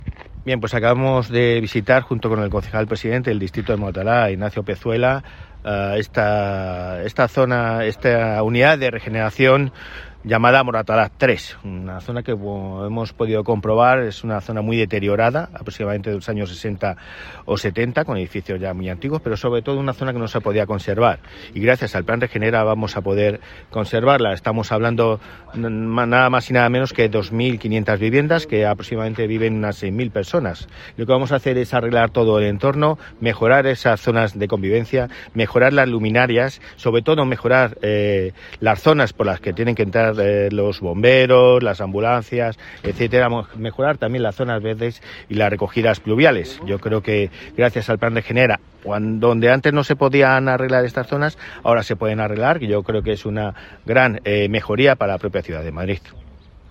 Nueva ventana:El delegado de Políticas de Vivienda y presidente de la Empresa Municipal de la Vivienda y Suelo de Madrid (EMVS Madrid), Álvaro González: